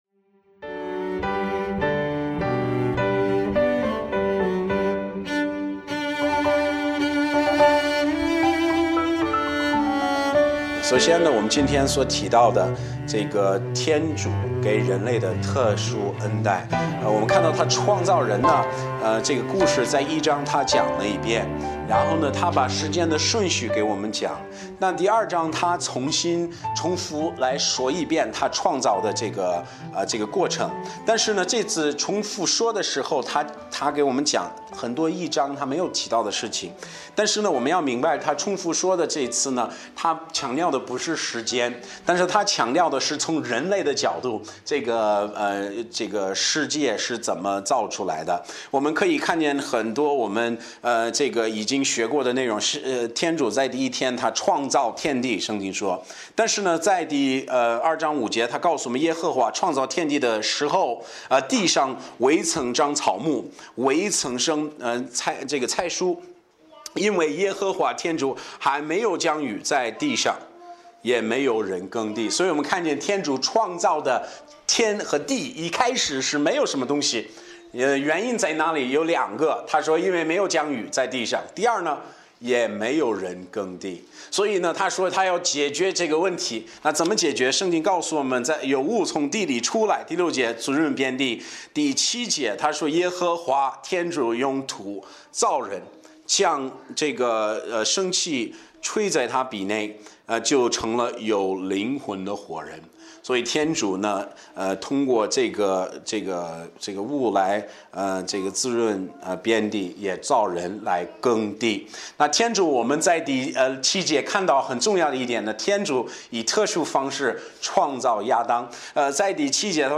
牧师